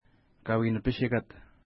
Pronunciation: ka:wi:nəpiʃeka:t
Pronunciation